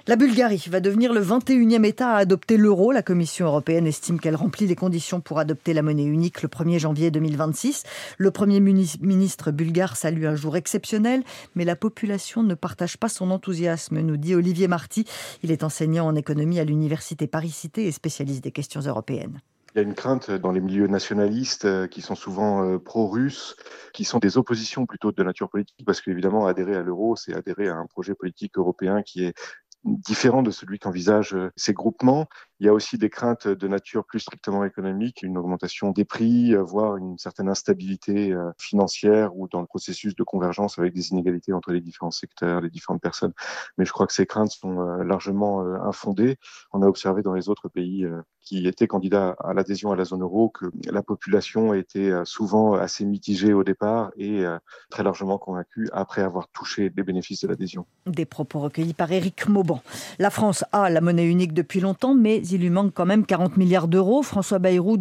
Interview pour Radio Classique